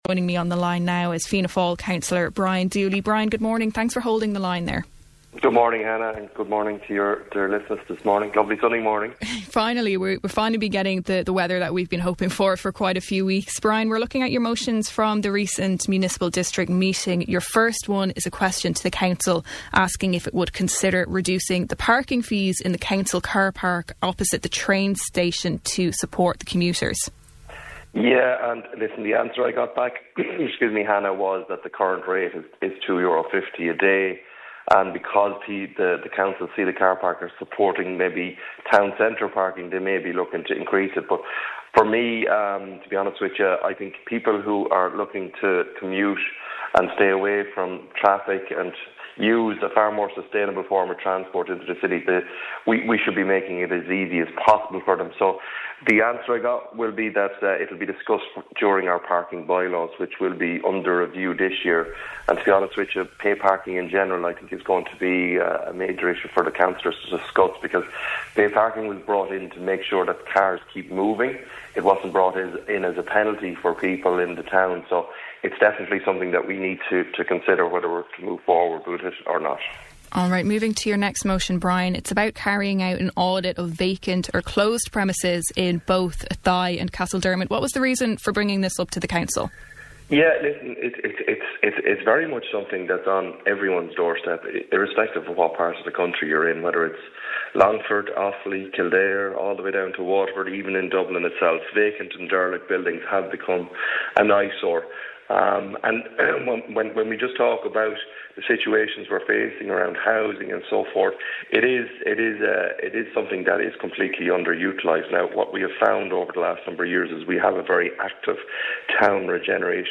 Speaking on Kildare Today, Cllr Dooley said that it is something that is "on everyone's doorstep", adding that such buildings have become an "eyesore".